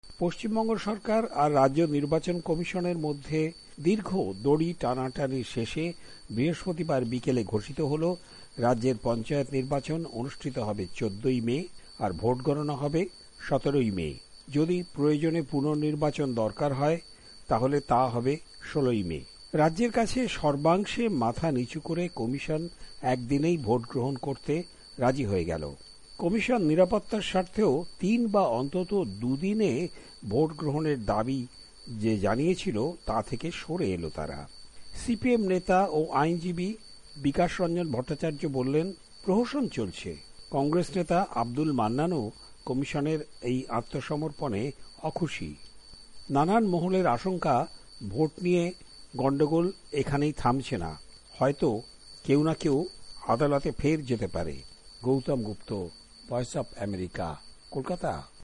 রিপোর্ট (পঞ্চায়েত)